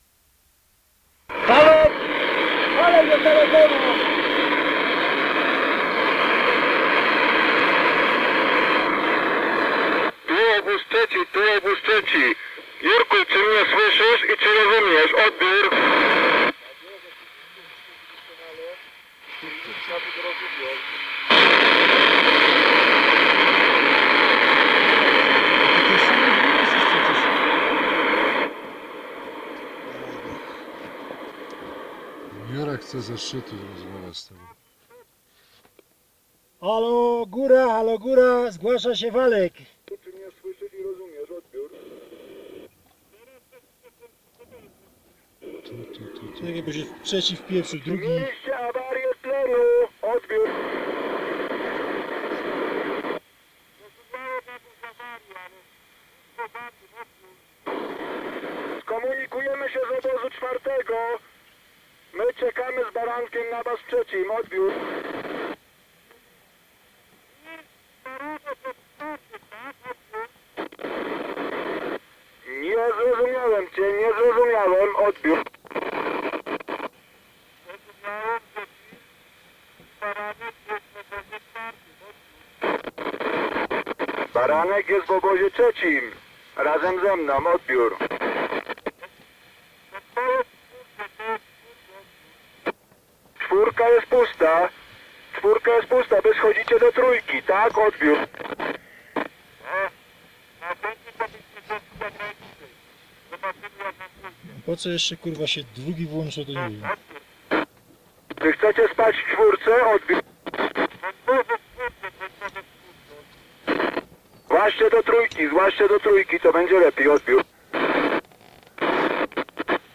Nagrania łączności radiowej z wypraw wysokogórskich – audialna podróż w przeszłość
Kasety magnetofonowe w pudełkach ochronnych
Fragment 4 – łączność radiowa z Jerzym Kukuczką na szczycie (Lhotse 1979)
Lhotse4-Kukuczka-szczyt.mp3